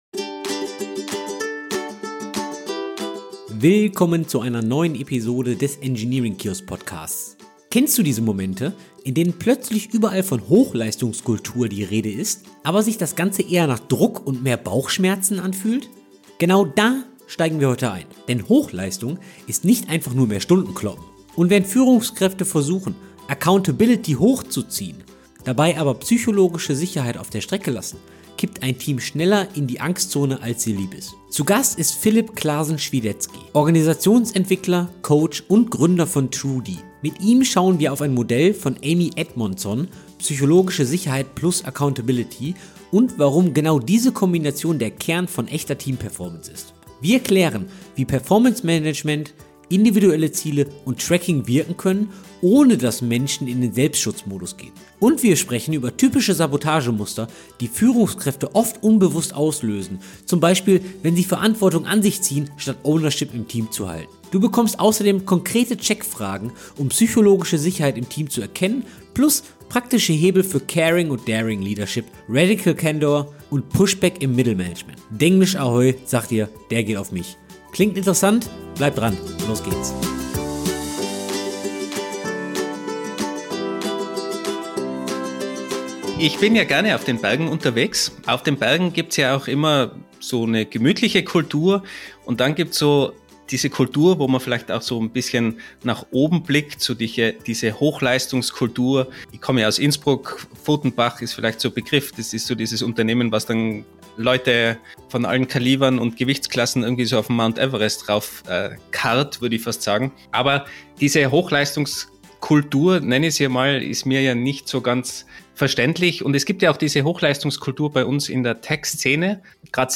In dieser Interview-Episode holen wir uns dafür Verstärkung von